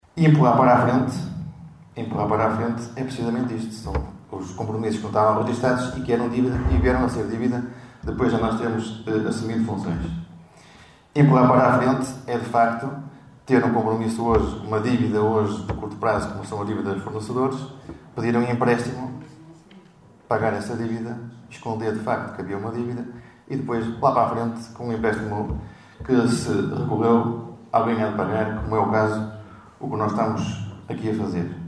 Excertos da última Assembleia Municipal.